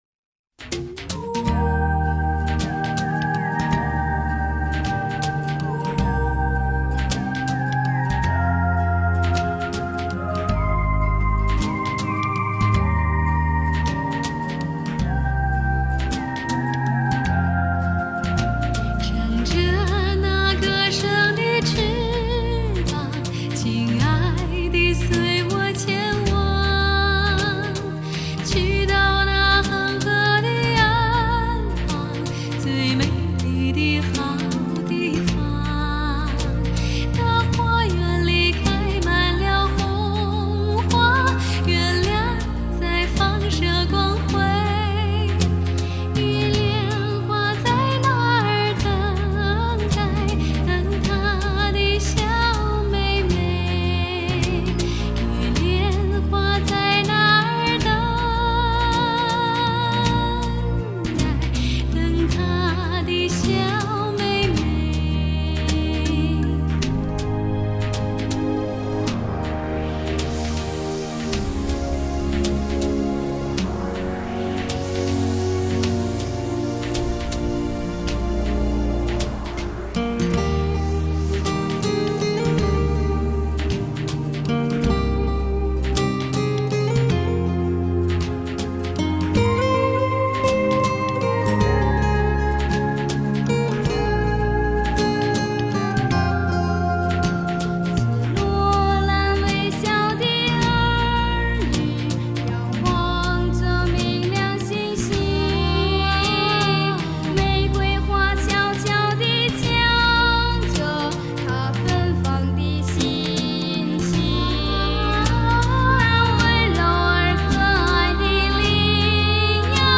中国跨界女声与清纯童声的深情对话